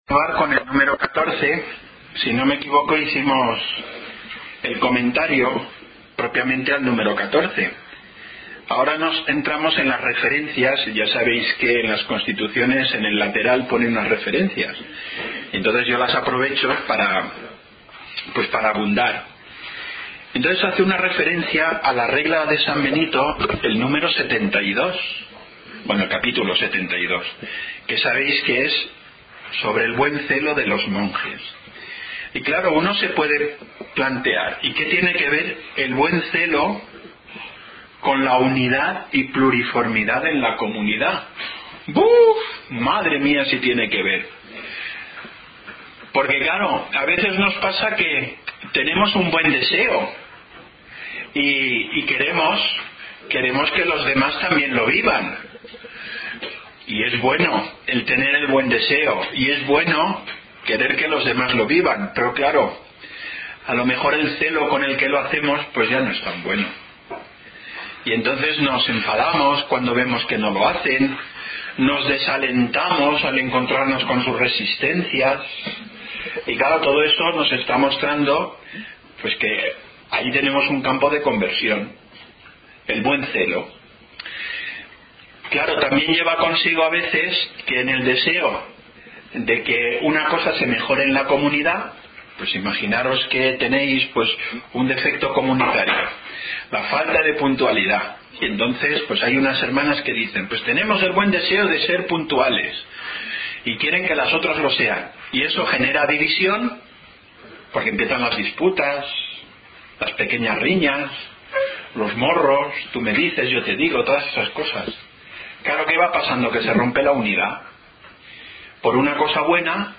Hemos querido rescatar una conferencia de formación que tuvimos hace tiempo, pero que creemos que puede ser útil a las personas que deseen conocer más de cerca nuestra realidad.